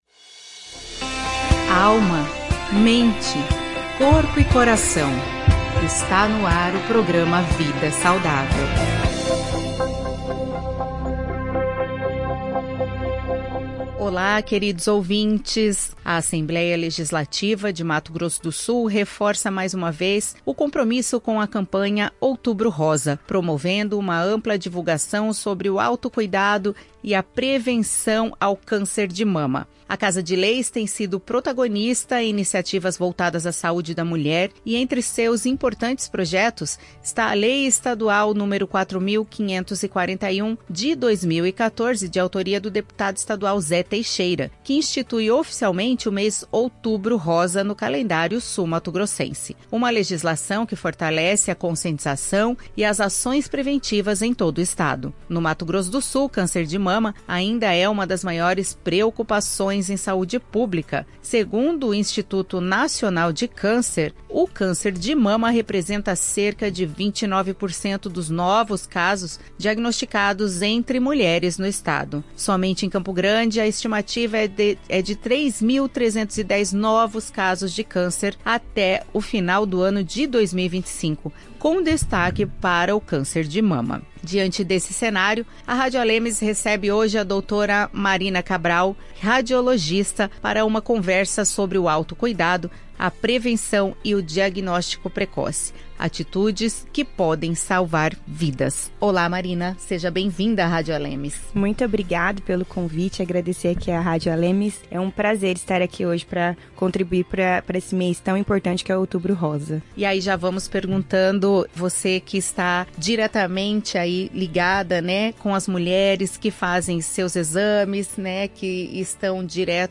entrevista especial